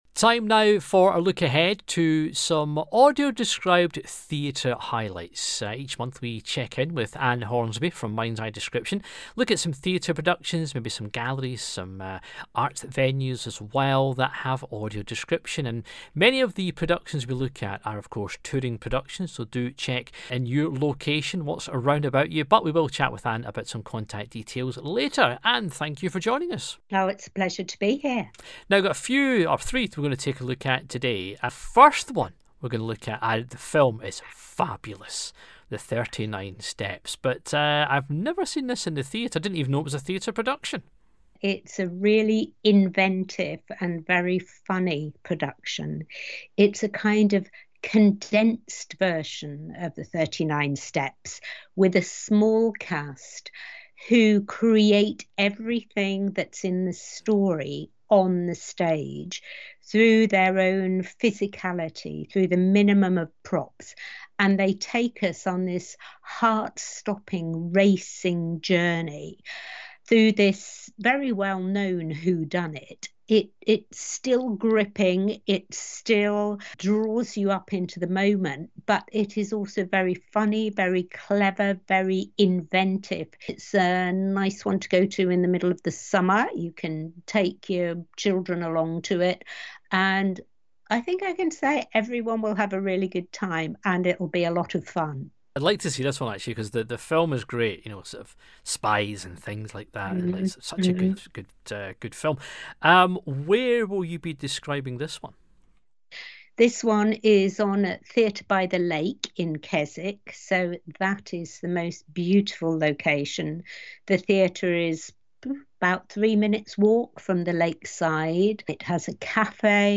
Audio Description is a narration which describes what is physically happening, say on stage, the big screen or in museums, galleries or other arts events.